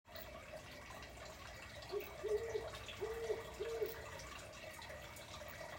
The Great Horned Owl is widespread and most definitely does hoot. It’s deep, sonorous whoo, whoo is mysterious and evocative.
I was out on our back deck after sunset one evening when I heard a pair of owls calling back and forth to one another.
Owl-again.m4a